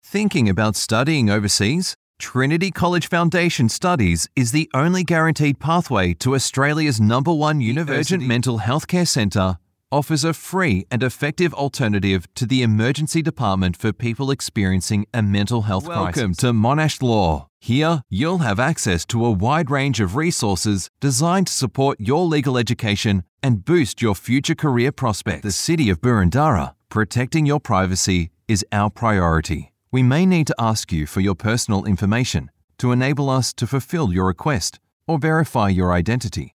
30s - 40s
Male
Australian
Natural
Hard Sell
Narration